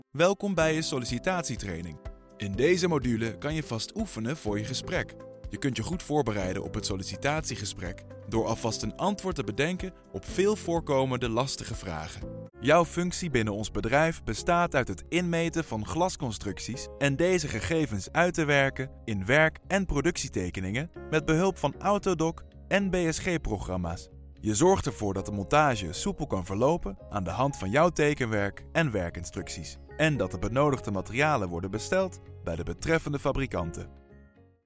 Sprechprobe: Industrie (Muttersprache):
Fluent in both Dutch and English, and with a wide range of voice, from a warm low to an energetic high.